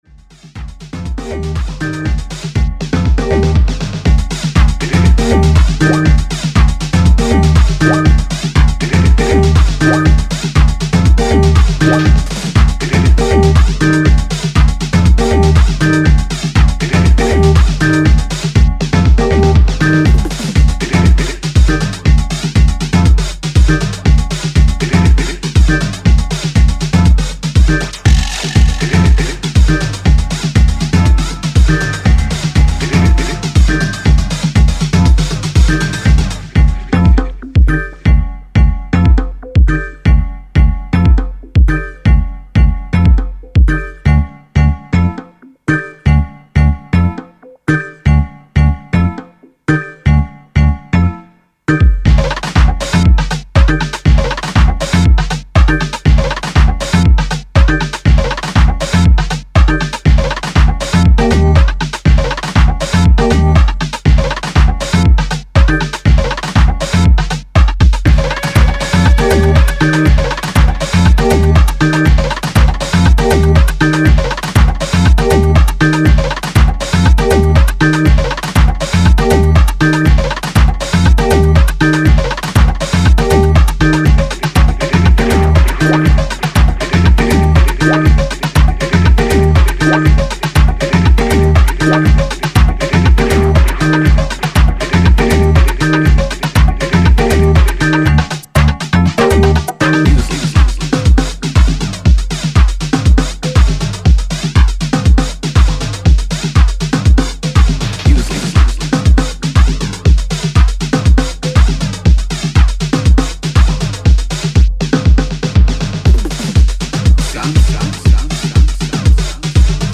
Style: Classic House & Techno